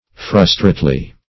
frustrately - definition of frustrately - synonyms, pronunciation, spelling from Free Dictionary Search Result for " frustrately" : The Collaborative International Dictionary of English v.0.48: Frustrately \Frus"trate*ly\, adv.
frustrately.mp3